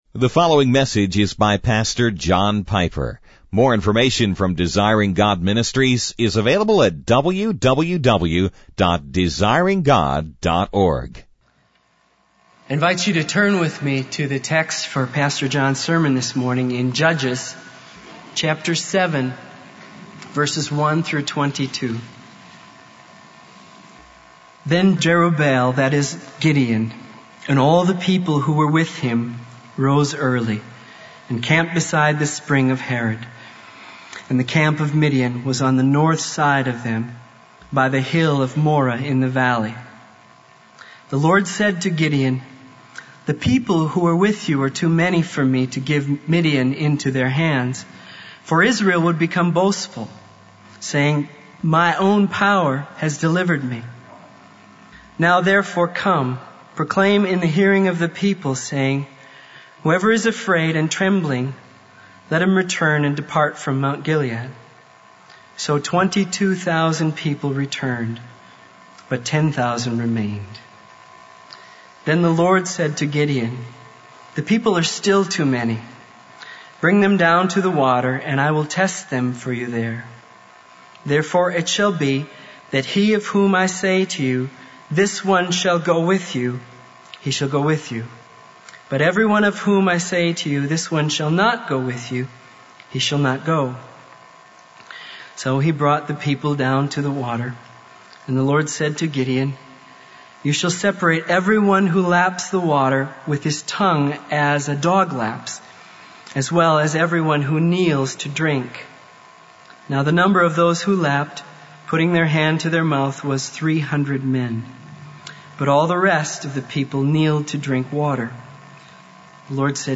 In this sermon, the speaker focuses on the story of Gideon from the Bible.